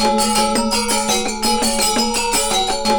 GAMELAN 2.wav